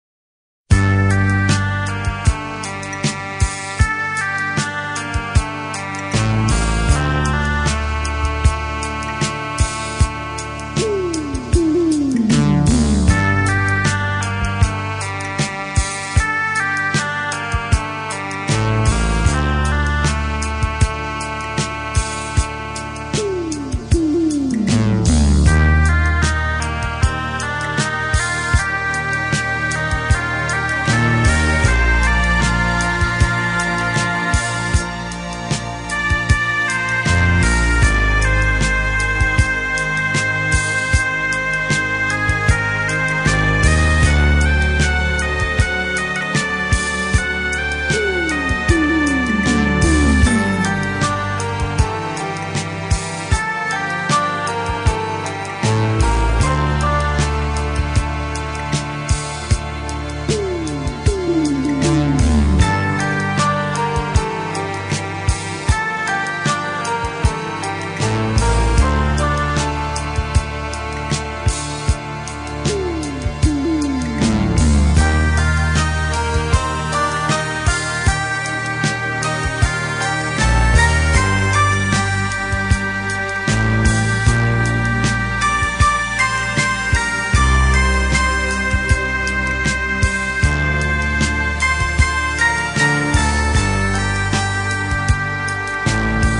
partitura electrónica